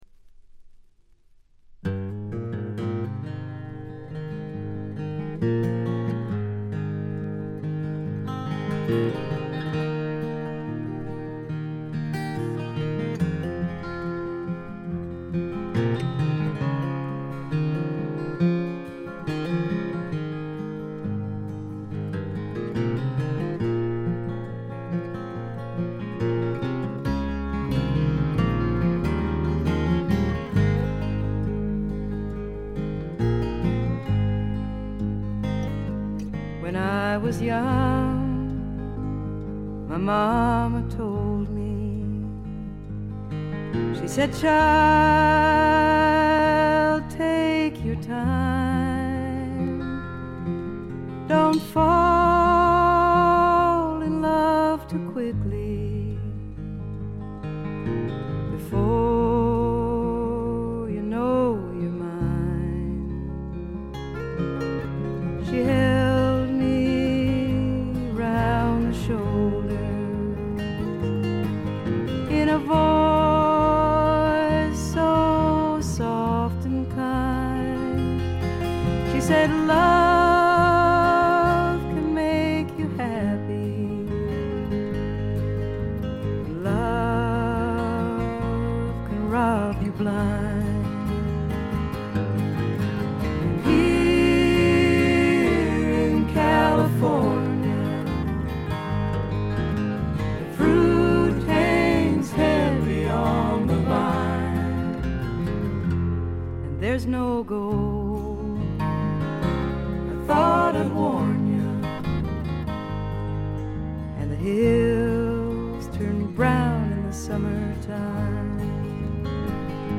ほとんどノイズ感無し。
本作もしみじみとした歌の数々が胸を打つ女性フォーキー・シンガーソングライターの基本です。
試聴曲は現品からの取り込み音源です。